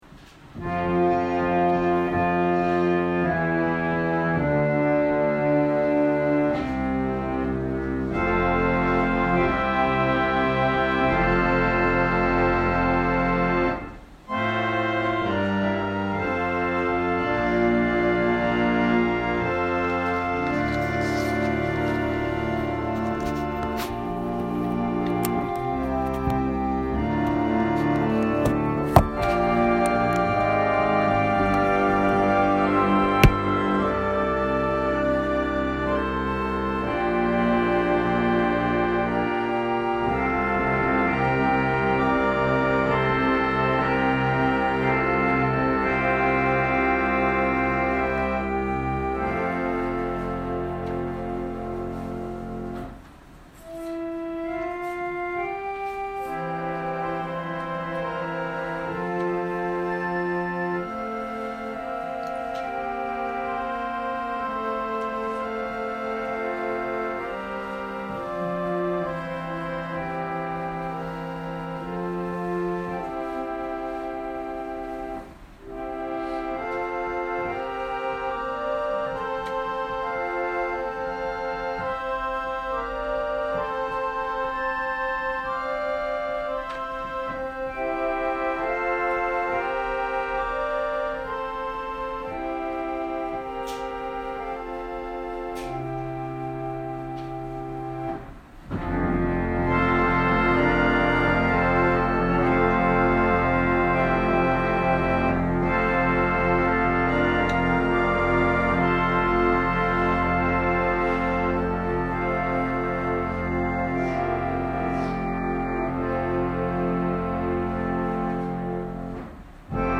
私たちは毎週日曜日10時20分から12時まで神様に祈りと感謝をささげる礼拝を開いています。
音声ファイル 礼拝説教を録音した音声ファイルを公開しています。